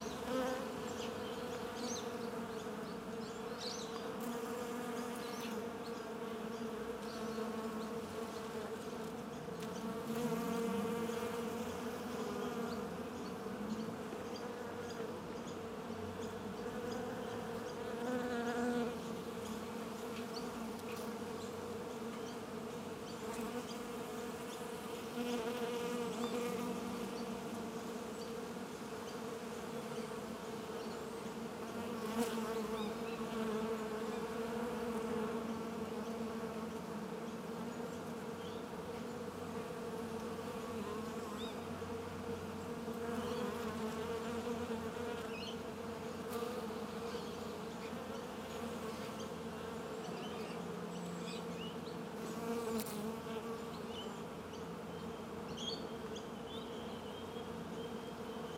Звуки мухи
Звук мух над кучей или на лугу: Жужжание у мусорной кучи